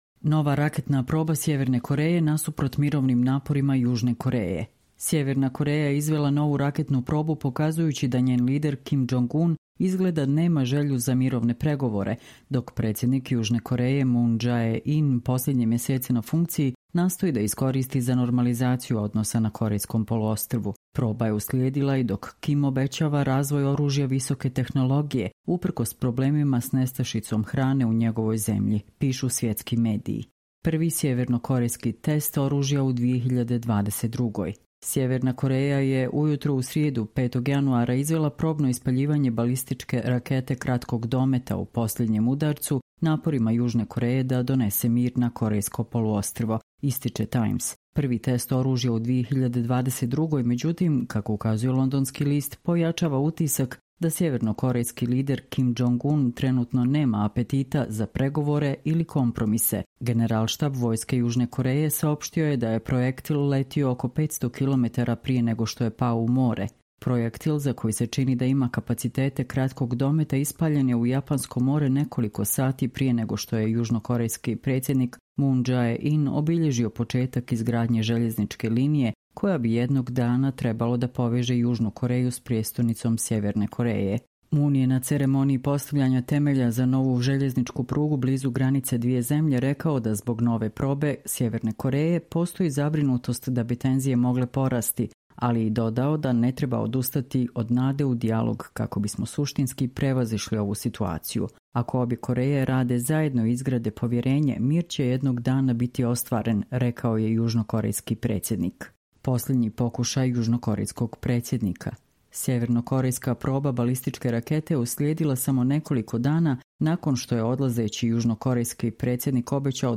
Čitamo vam: Nova raketna proba Severna Koreja nasuprot mirovnim naporima Južne Koreje